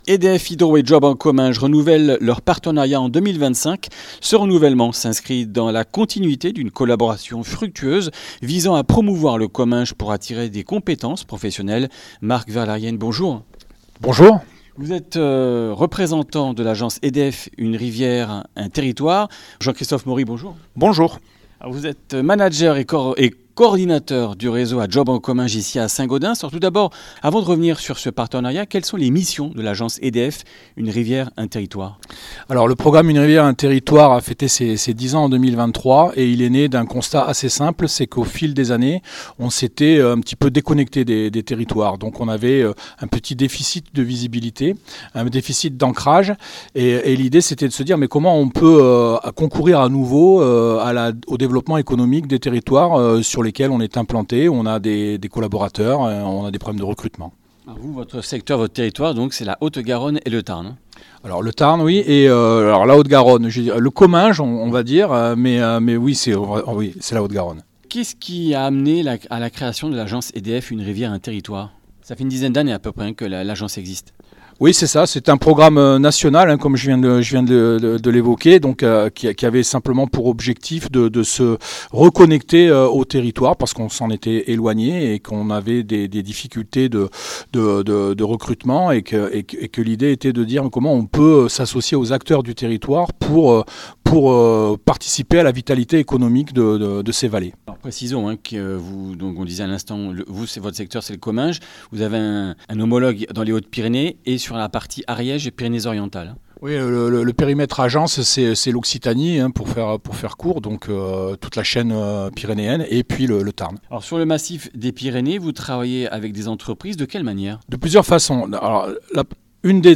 lundi 14 avril 2025 Comminges Interviews Durée 15 min
Une émission présentée par